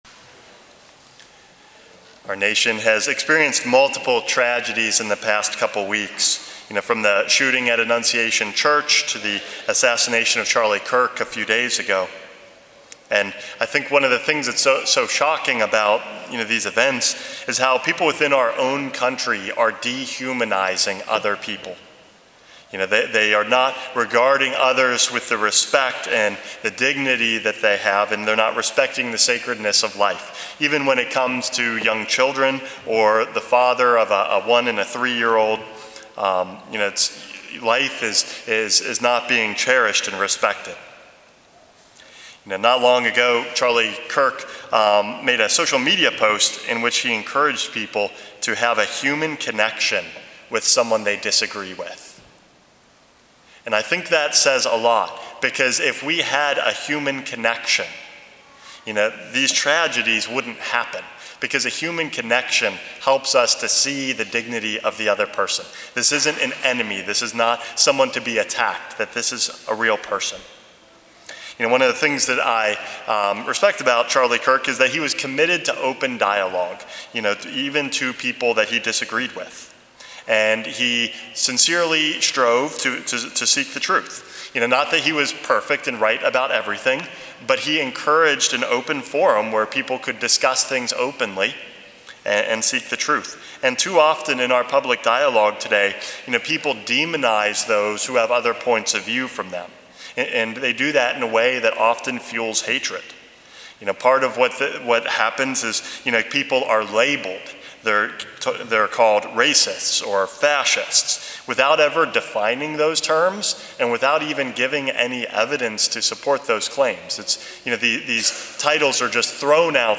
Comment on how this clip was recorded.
Exaltation of the Holy Cross Year C